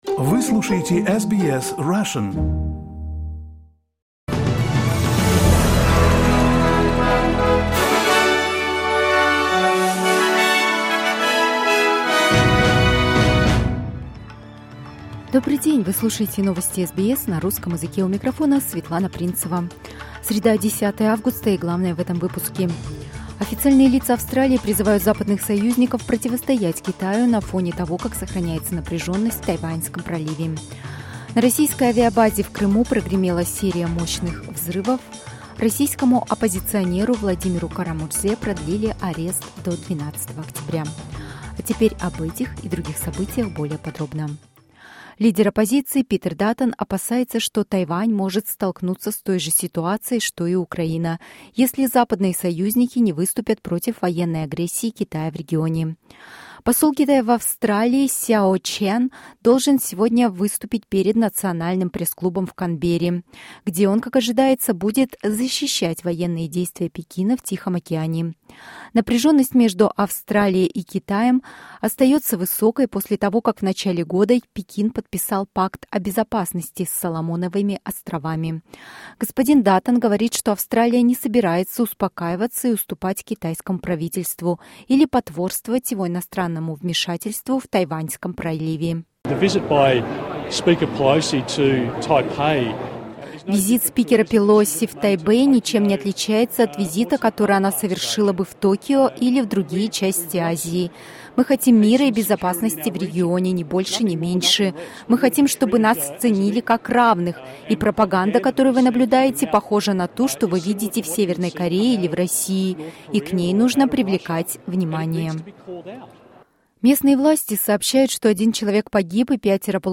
SBS News in Russian - 10.08.22